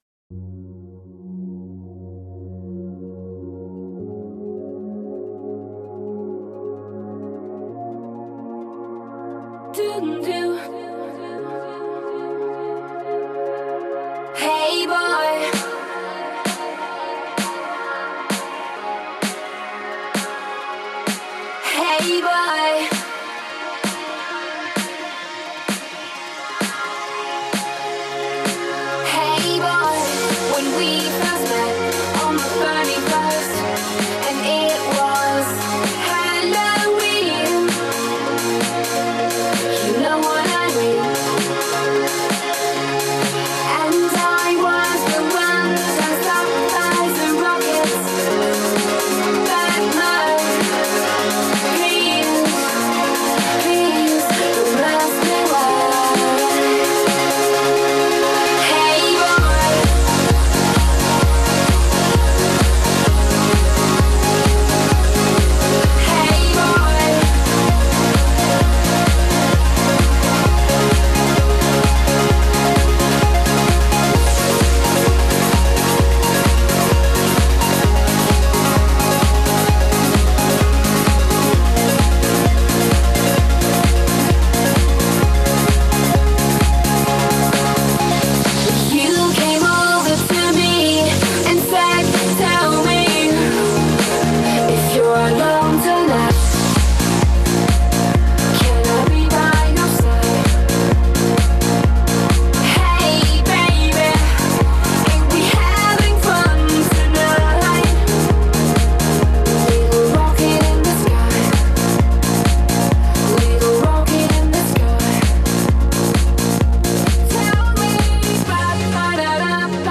Dance Данс музыка Dance music